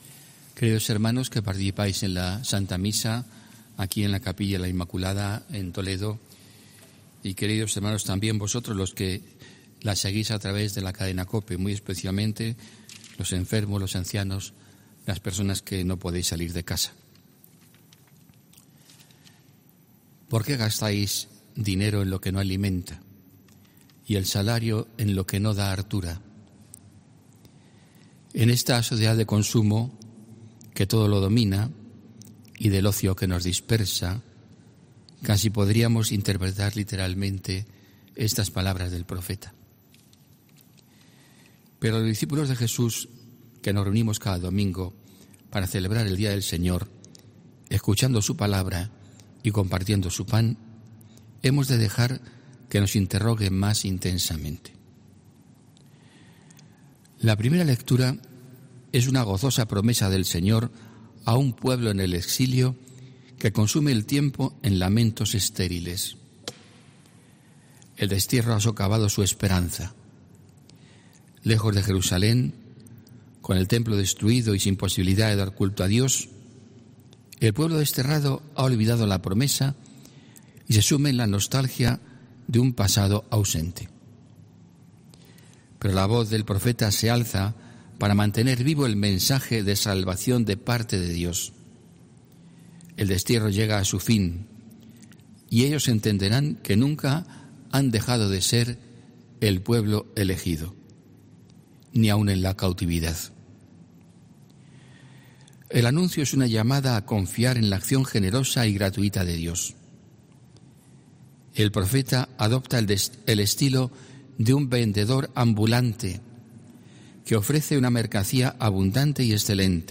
HOMILÍA 2 AGOSTO 2020